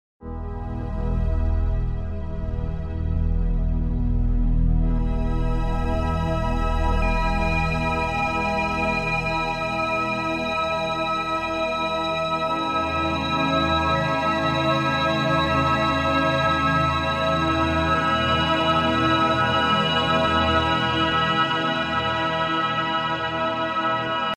Authentic 323.634Hz = 432Hz in sound effects free download
Authentic 323.634Hz = 432Hz in E4 Note meditation